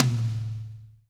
-DRY TOM 4-L.wav